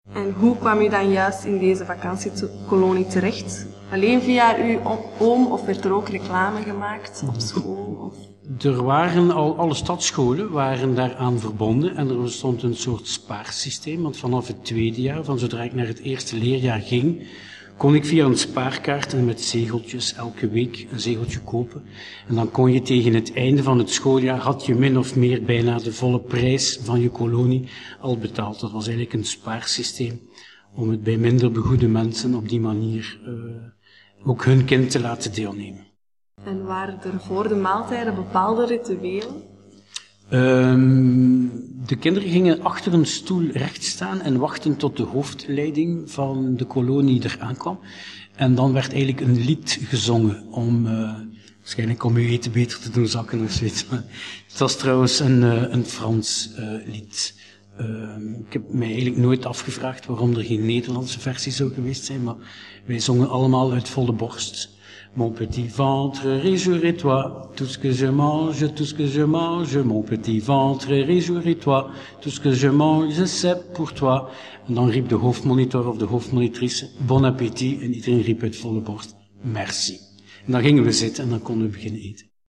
Audiofragmenten - Mondelinge getuigenissen PDF Afdrukken E-mail
Interview door studenten UGent in het kader van partim mondelinge geschiedenis.